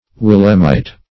Search Result for " willemite" : The Collaborative International Dictionary of English v.0.48: Willemite \Wil"lem*ite\, n. [From Willem I., king of the Netherlands.]